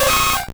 Cri de Nidoran♂ dans Pokémon Or et Argent.